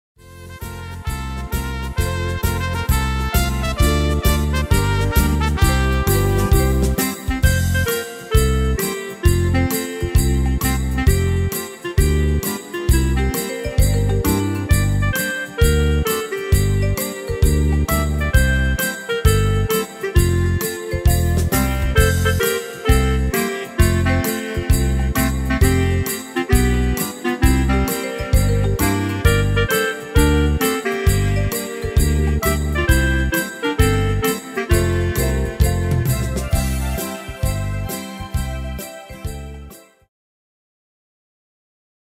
Tempo: 132 / Tonart: F-Dur